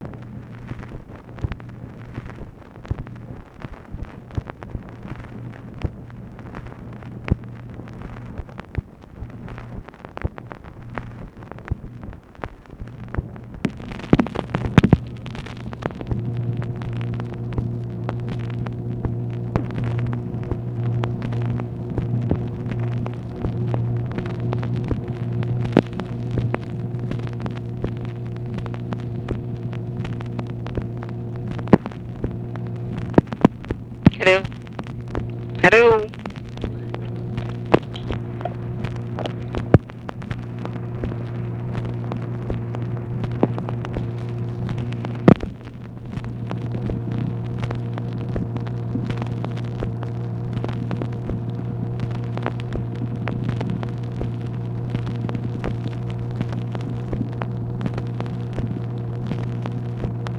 UNIDENTIFIED SPEAKER SAYS "HELLO, HELLO" THEN RECORDING IS BLANK
Conversation with UNIDENTIFIED VOICE, May 18, 1965